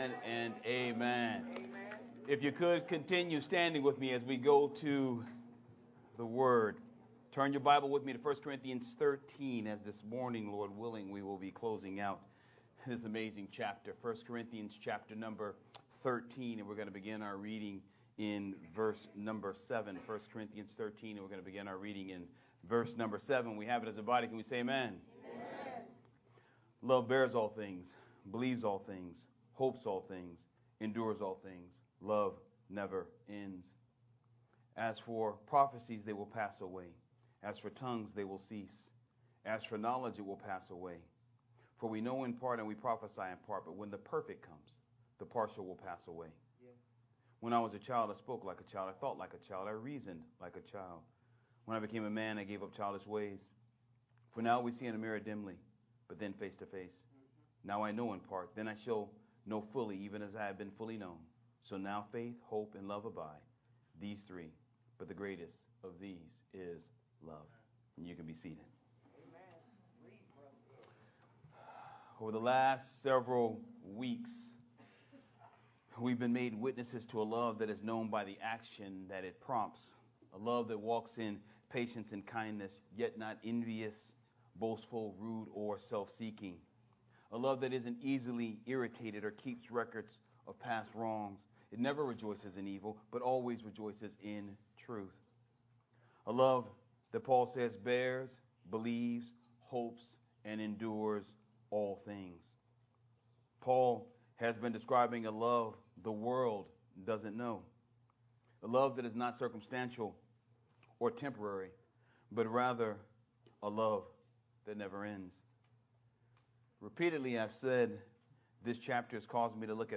Sermon from 1 Corinthians 13:8-13